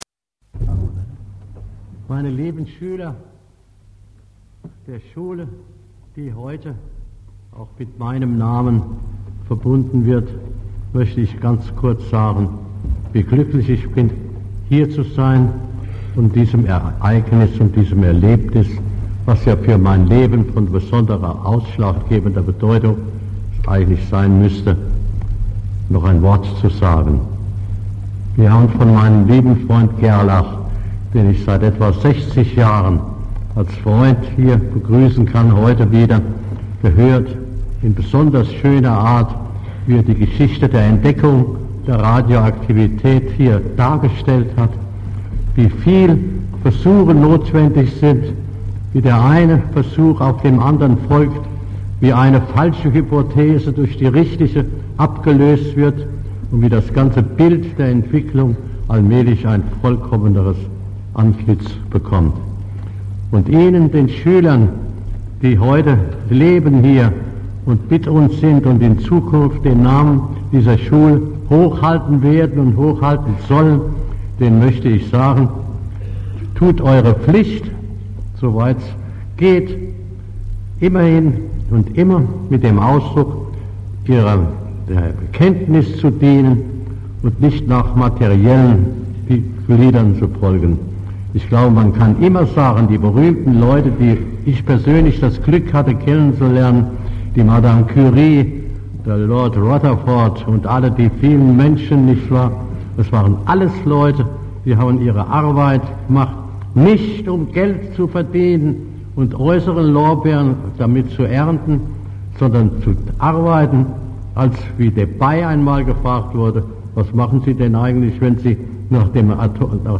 Zur Namensgebung besuchte er Landau und hielt auf dem Festakt in der Jugendstilfesthalle seine letzte veröffentliche Rede.
Audio: Rede zur Eröffnung des OHGs von Otto Hahn (Ausschnitt, ca. 3,5 MB)
otto-hahn_eroeffnungsrede.mp3